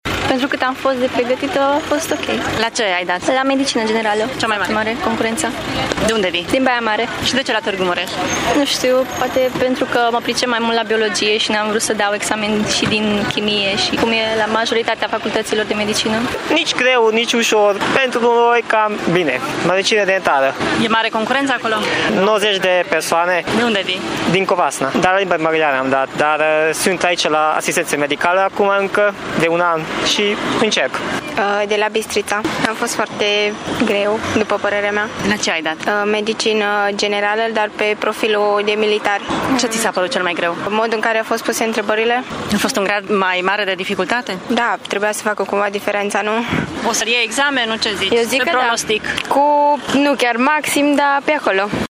Cel mai dificil test a fost la medicină generală, au spus candidații iesiți din examen. Mulți concurenți au venit din alte județe: